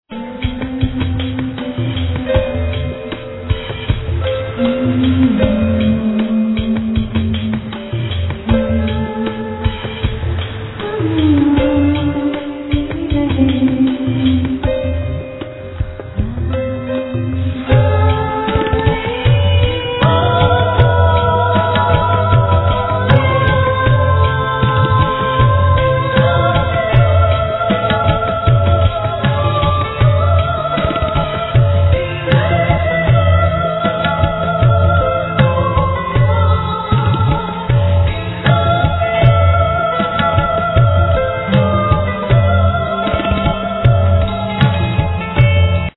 Yang T'Chin,Santoor,Rhythm,Voice
Voices, Snare drums, Percussions
Keyboards, Timpani, Shamanic Drums, Percussions
Voices, Timpani, Singing bows, Percussions